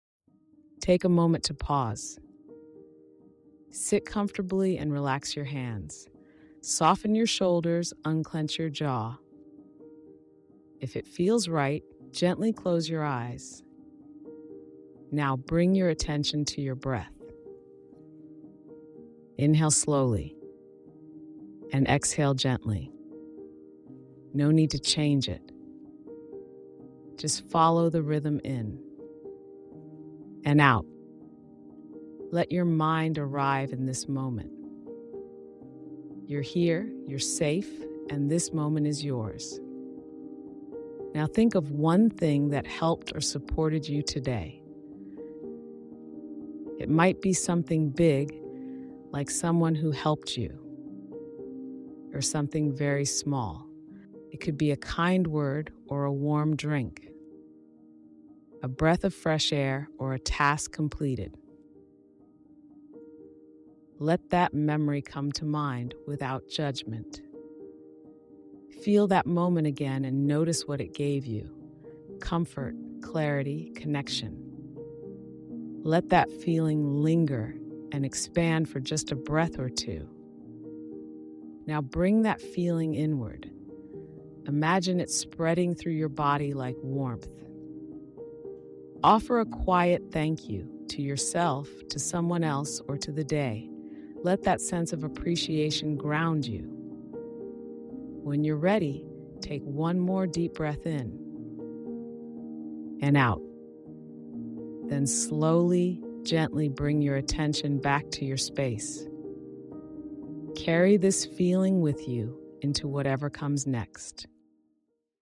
This short, guided practice helps you pause and reconnect to something that supported you today. Use this as an end-of-day ritual or a midday reset.